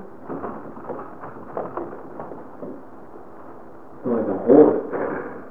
Horse Running
Olympus Digital VN-1800
Sounds as if a horse was running through the church.
horse.wav